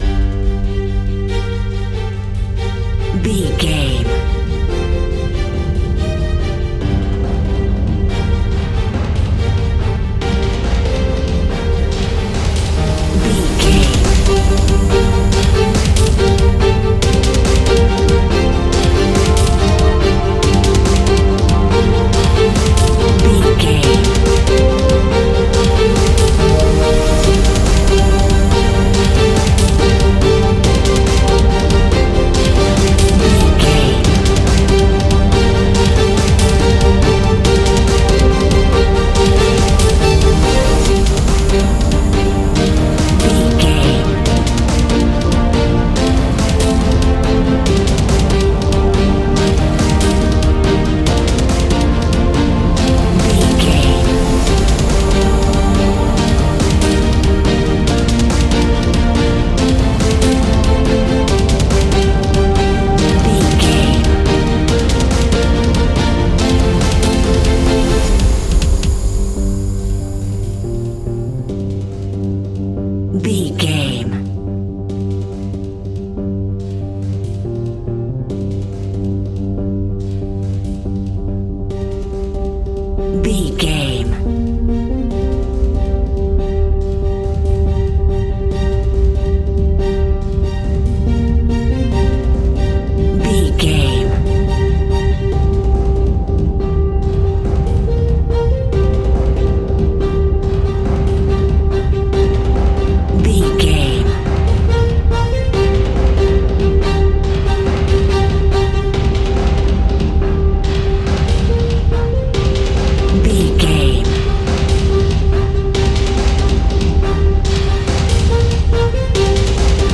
Aeolian/Minor
angry
aggressive
electric guitar
drums
bass guitar